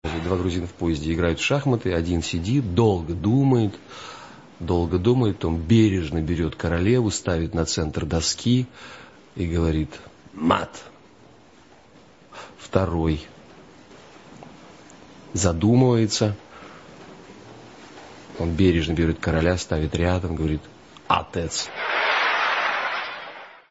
Звуки анекдотов